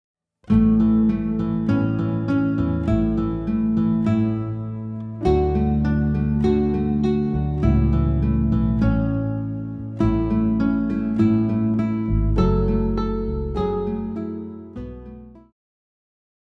guitar arrangement preview